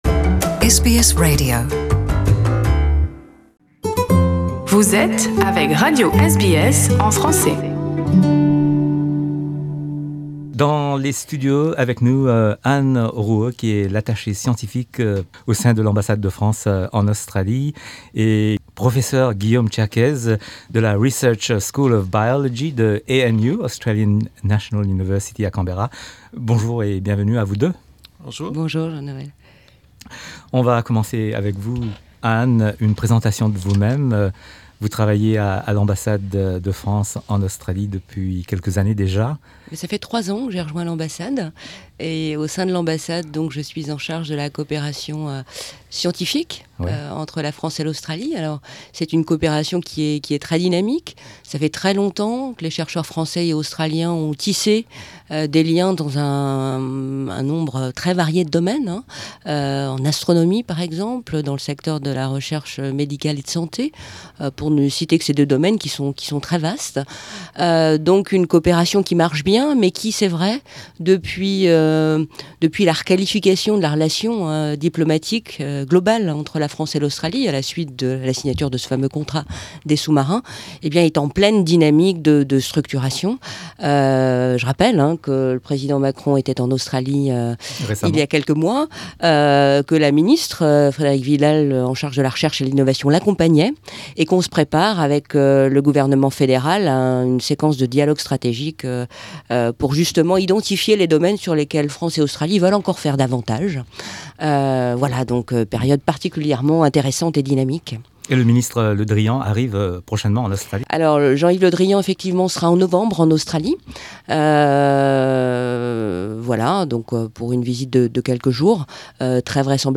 à l’occasion de l’inauguration de l’exposition ‘The Flora of the Paris Basin’ à l’Alliance française de Melbourne.